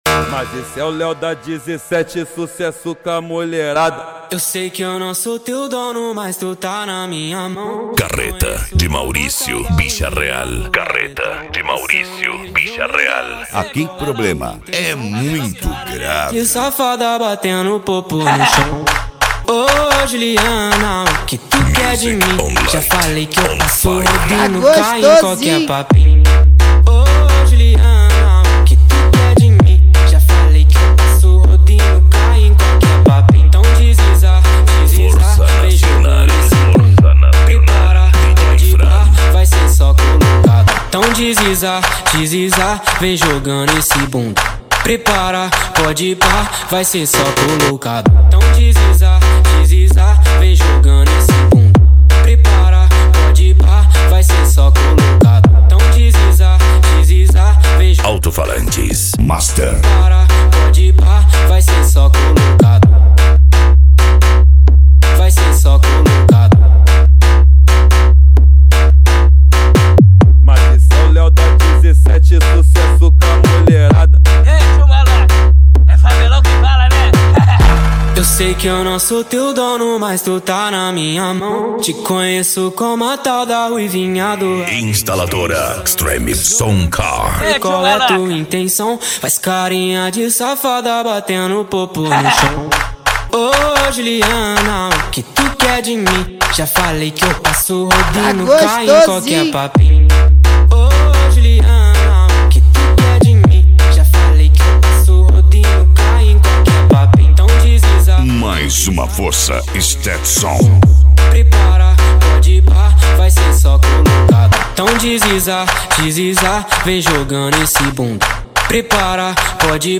Bass
PANCADÃO
Psy Trance
Remix